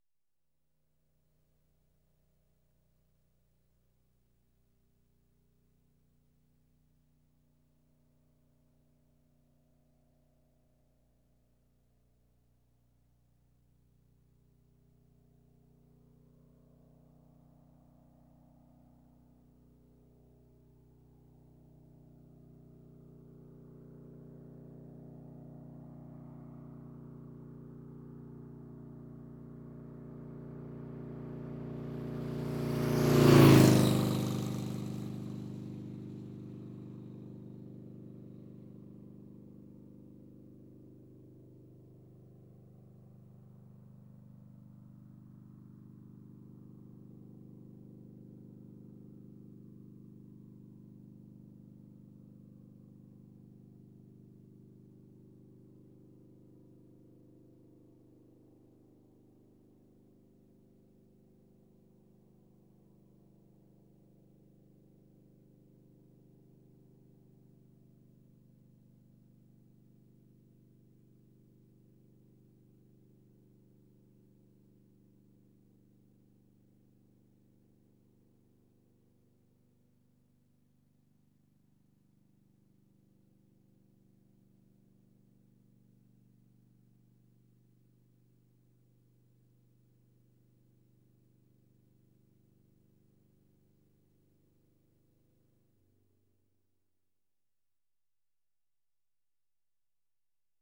Звуки мотоцикла
Мотоциклист мчится издалека и с визгом проносится мимо, растворяясь вдали